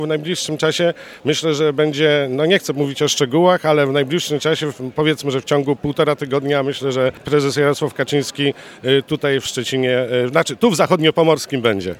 Prezes Prawa i Sprawiedliwości Jarosław Kaczyński w sobotę odwiedzi Dolny Śląsk, a w przyszłym tygodniu przyjedzie do województwa zachodniopomorskiego. Poinformował o tym poseł Artur Szałabawka z PiS.